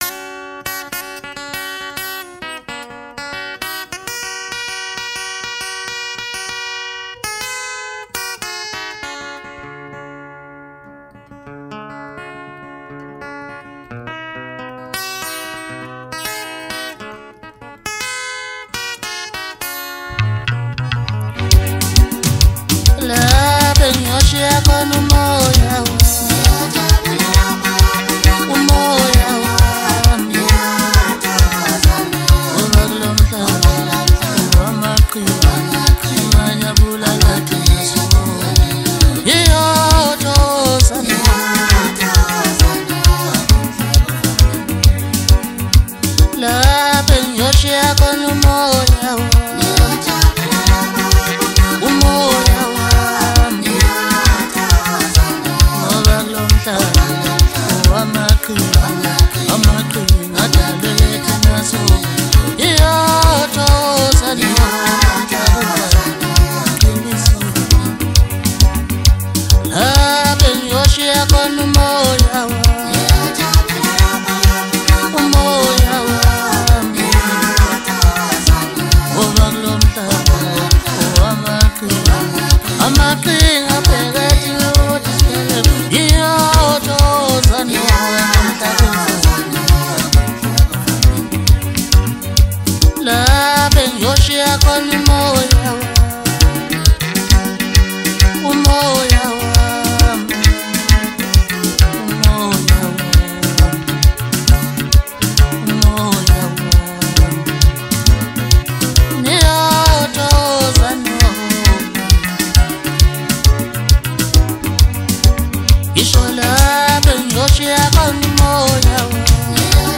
MASKANDI MUSIC
maskandi song